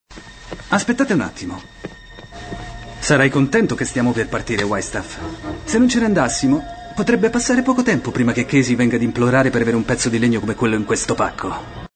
nel film "Ski College", in cui doppia Oliver Macready.